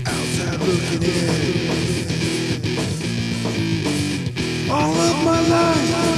We had fun with the echo box.